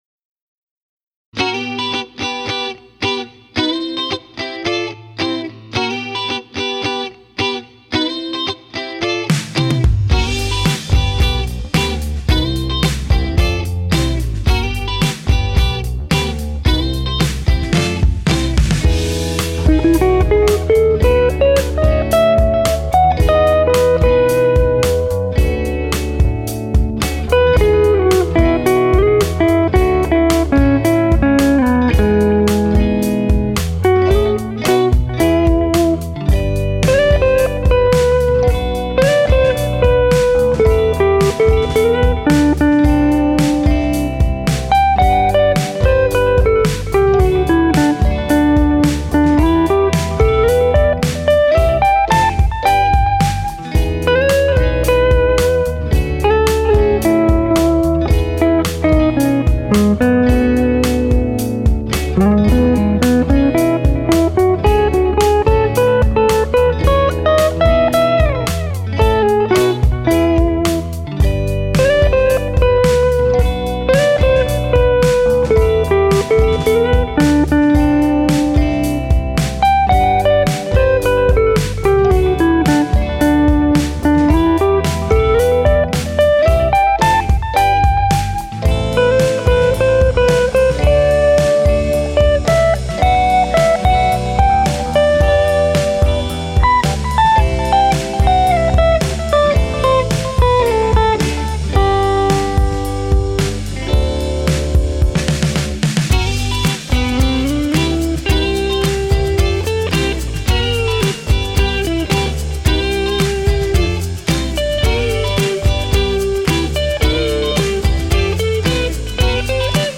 This instrumental was based on a riff that I had been fooling with for years, sort of a cross between funk and chicken pickin’. I ran my Telecaster with Texas Special pickups through a ToneX Dumble preset for this one. My Roland Juno synth was used for the Hammond type sound and the drums were created in Logic Pro X. My go-to-guy for bass